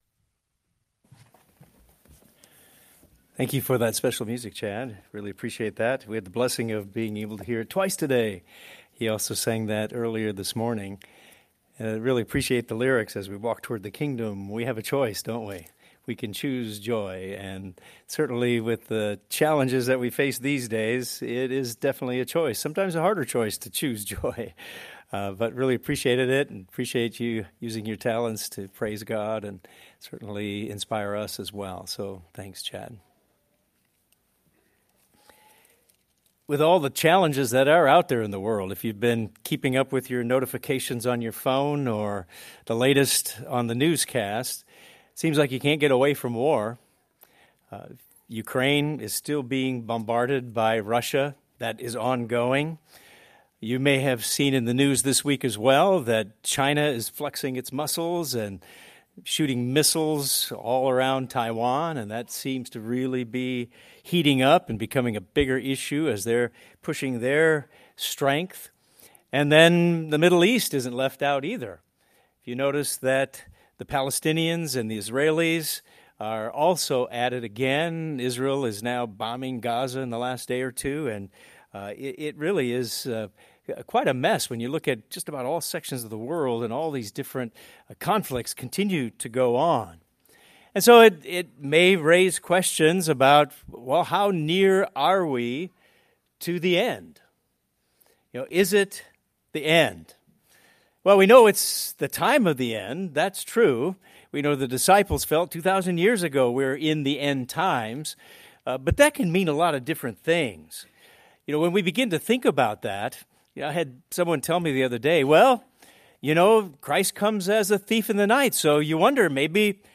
This sermon will discuss some of the prophesied events that must take place before the return of Christ and Jesus’ emphasis for His people.